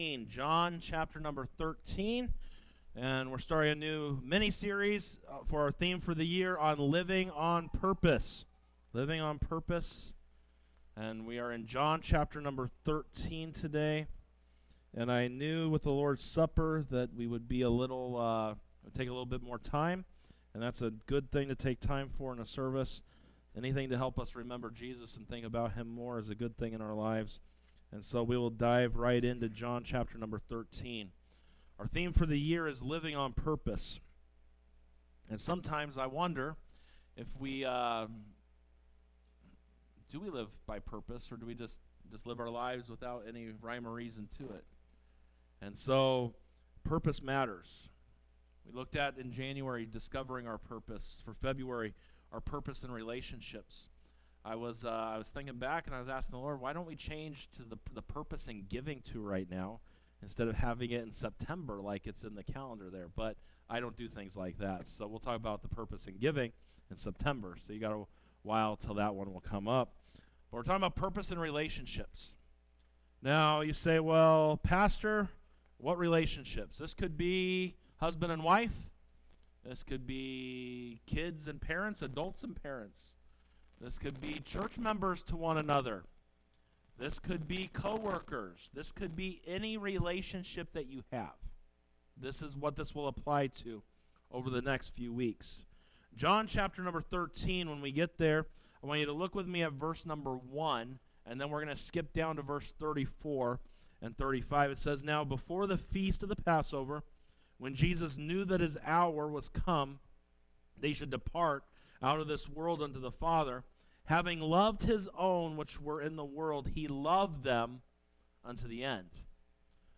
Sunday Worship Service 02:02:25 - Loving as Christ Loves US Part 1 by vbcchino